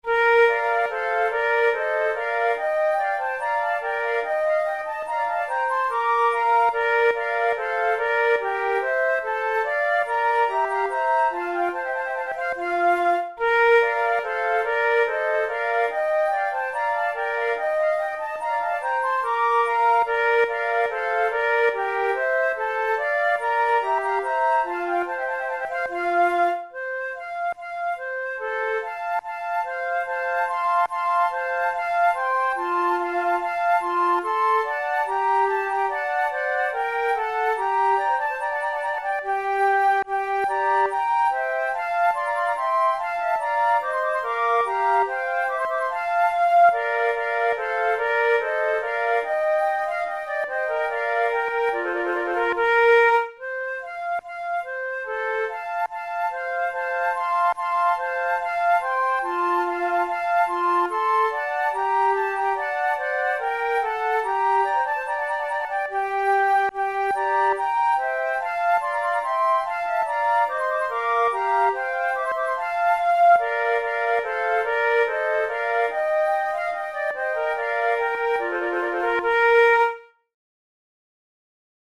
a sonata for 3 flutes in B-flat major